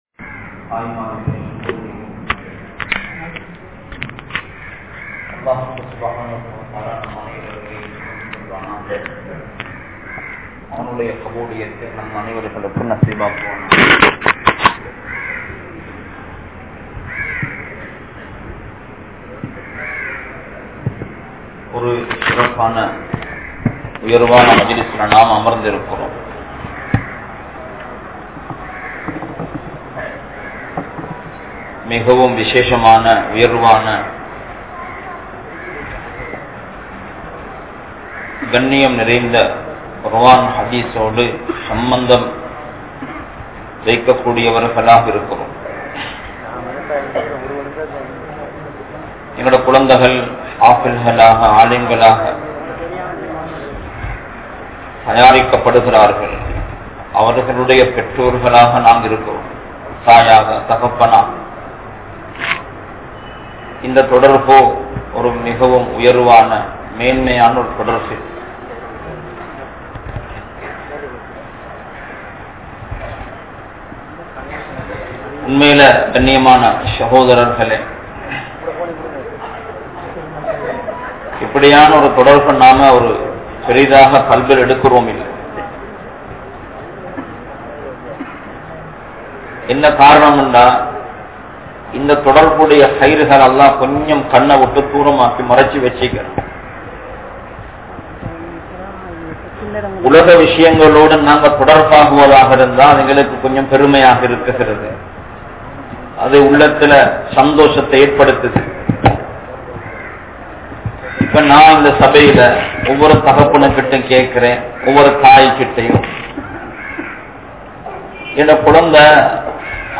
Vaalkaien Noakkam (வாழ்க்கையின் நோக்கம்) | Audio Bayans | All Ceylon Muslim Youth Community | Addalaichenai
Grand Jumua Masjith